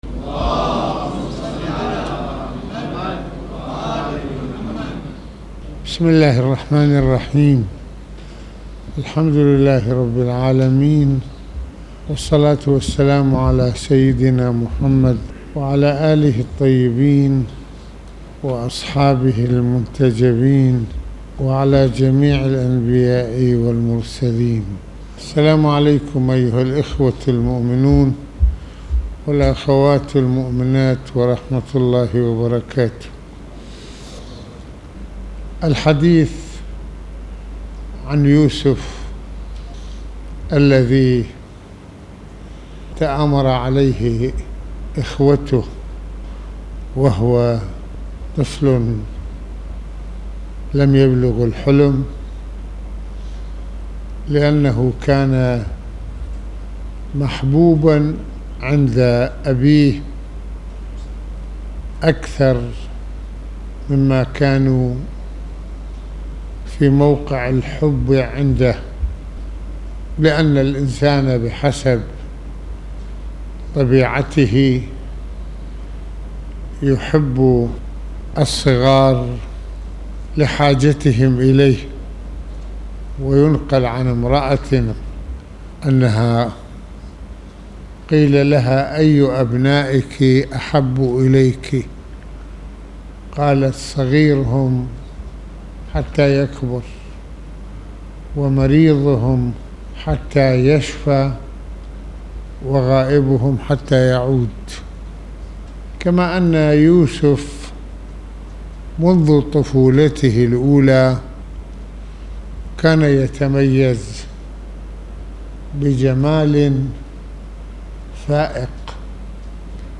خطبة الجمعة المكان : مسجد الإمامين الحسنين (ع)